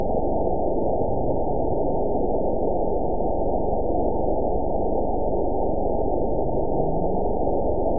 event 911990 date 03/14/22 time 19:07:19 GMT (3 years, 2 months ago) score 9.41 location TSS-AB04 detected by nrw target species NRW annotations +NRW Spectrogram: Frequency (kHz) vs. Time (s) audio not available .wav